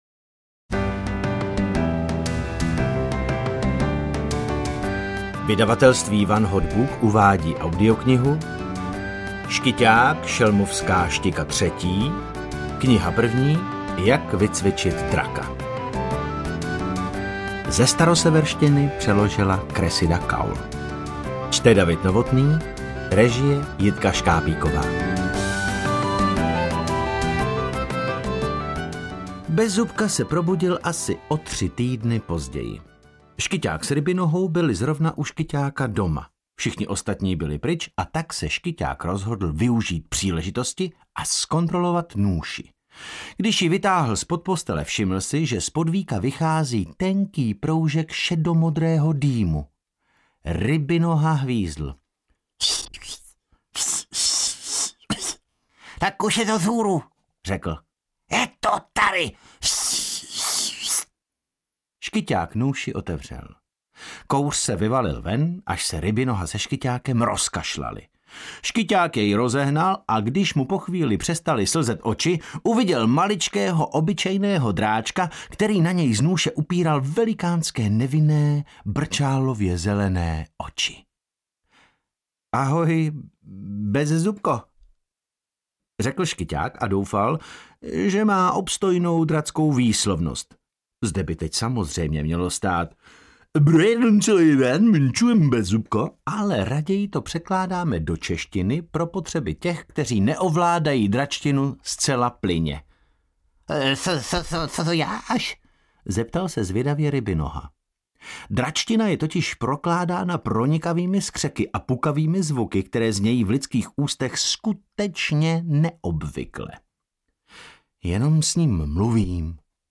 Ukázka z knihy
• InterpretDavid Novotný